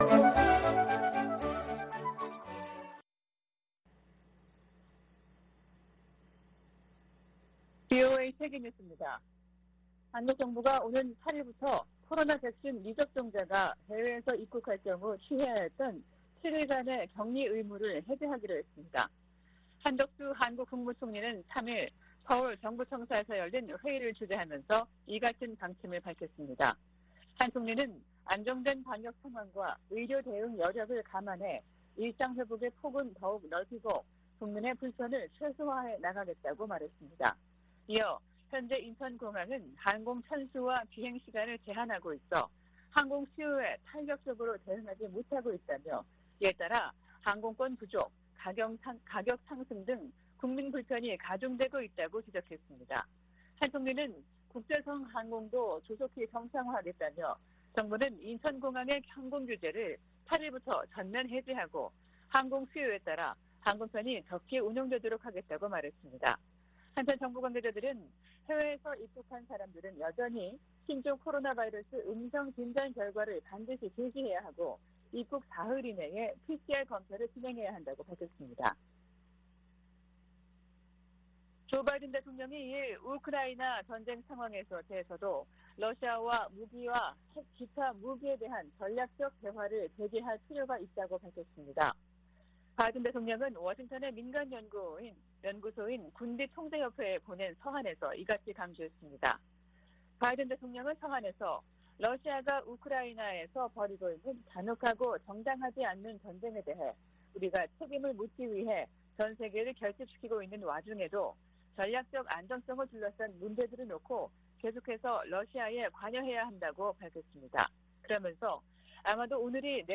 VOA 한국어 아침 뉴스 프로그램 '워싱턴 뉴스 광장' 2022년 6월 4일 방송입니다. 미국과 한국, 일본의 북핵 수석대표들은 북한의 7차 핵실험 준비 동향에 대해 긴밀한 공조를 통한 억제력 강화 등을 경고했습니다. 미 국무부는 2021년도 종교자유보고서에서 북한의 종교 탄압이 심각히 우려되고 있다고 지적했습니다. 웬디 셔먼 미 국무부 부장관은 북한에 추가 도발을 자제하고 대화의 길로 나올 것을 거듭 촉구했습니다.